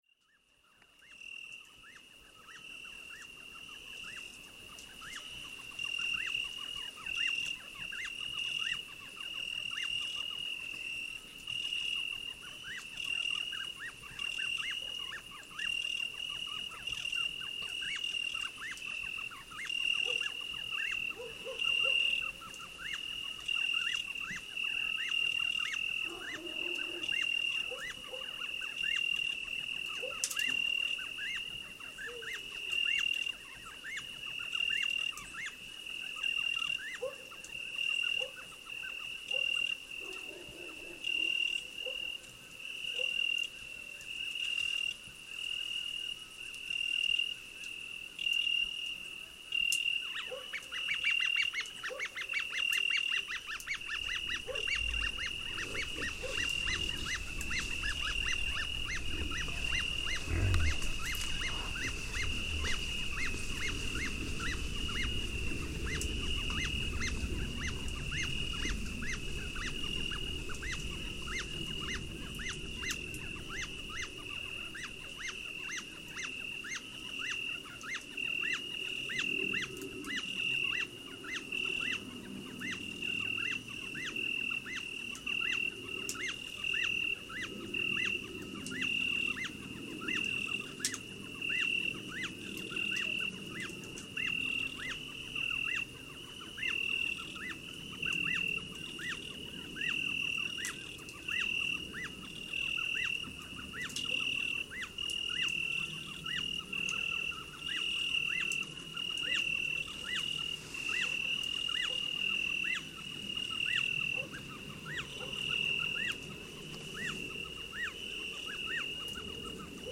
This is the classic warm evening soundscape high up in the mountains. Crickets, edible dormice, mountain dogs combine stridulations and vocalisations to form a rich and evocative ear massage. On this recording a wild boar briefly visits my microphones right by our garden fence.